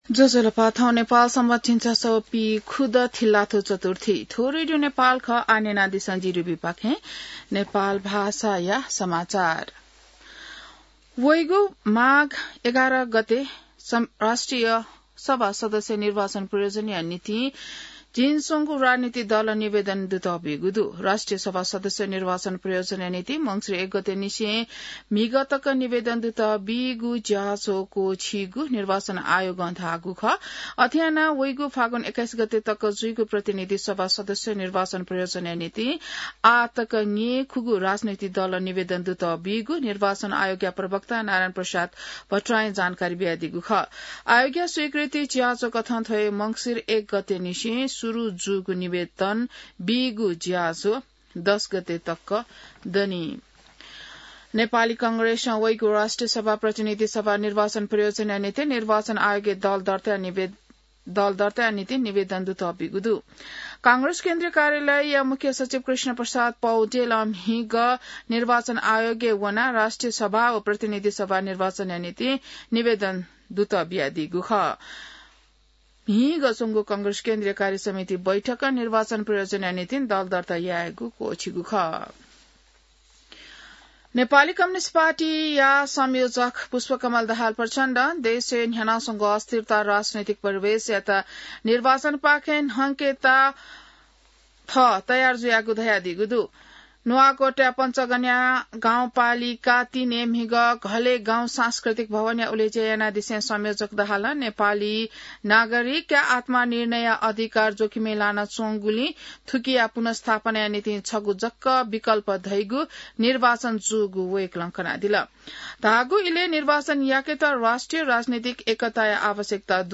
नेपाल भाषामा समाचार : ८ मंसिर , २०८२